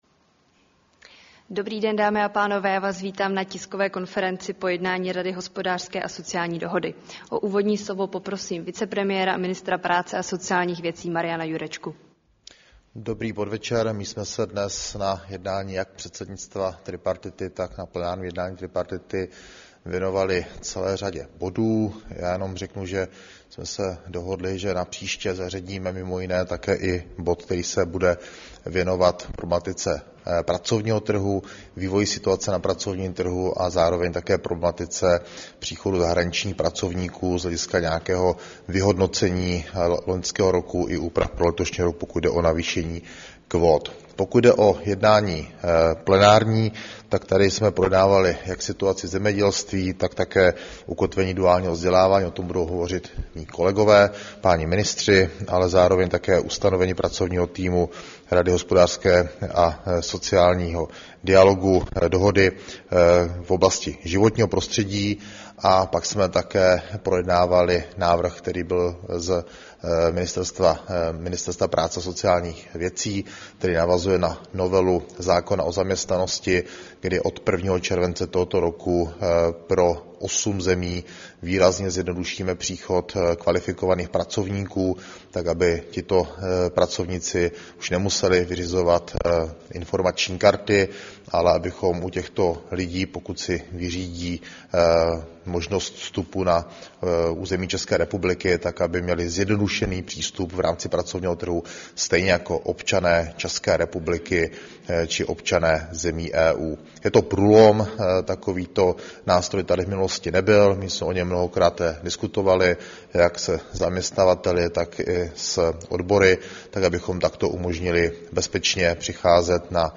Tisková konference po 175. plenárním zasedání Rady hospodářské a sociální dohody ČR